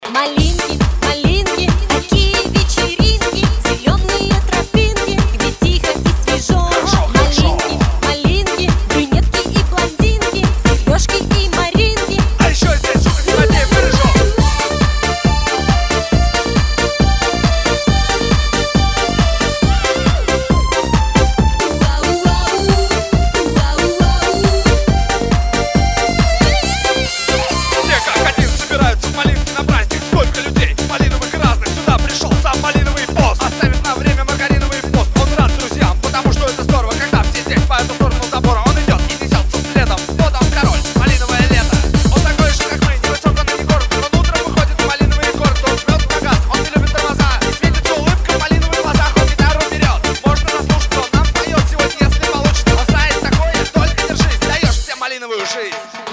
• Качество: 320, Stereo
позитивные
зажигательные
веселые
Eurodance
Hard dance